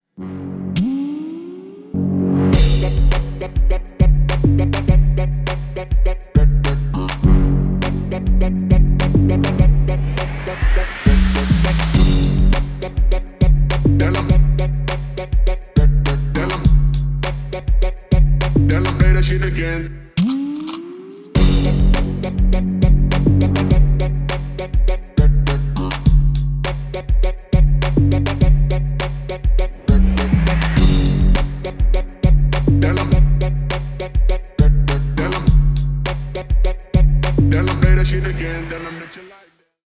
Ремикс
Электроника
без слов